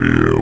VEC3 Percussion 092.wav